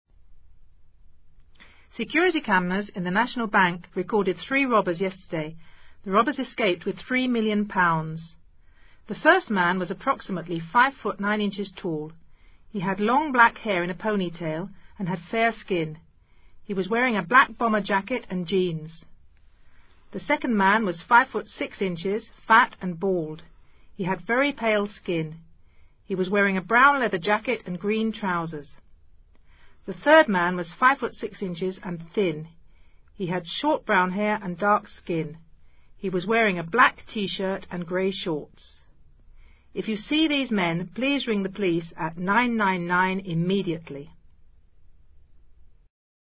Este registro recrea un comunicado televisivo sobre las características de tres supuestos ladrones. Se describe tanto la apariencia física de cada uno de ellos como su indumentaria.